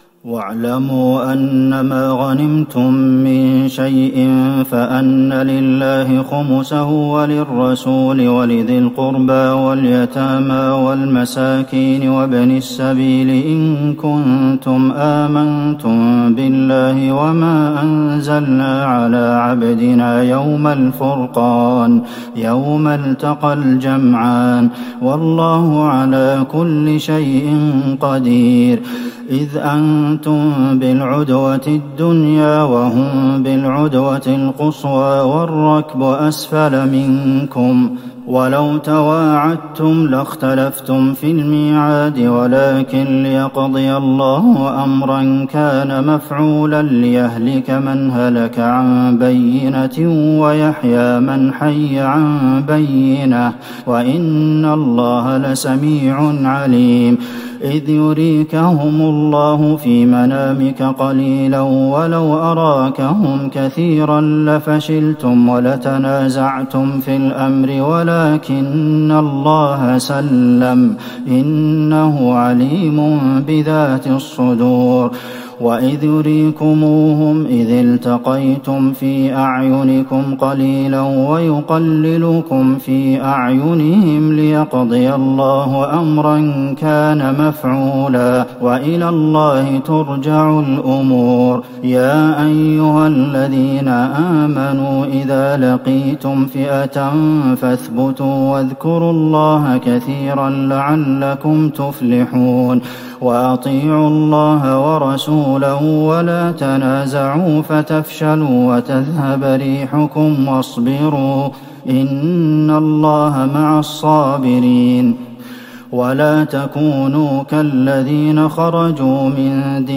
ليلة ١٣ رمضان ١٤٤١هـ من سورة الأنفال { ٤١-٧٥ } والتوبة { ١-٢٧ } > تراويح الحرم النبوي عام 1441 🕌 > التراويح - تلاوات الحرمين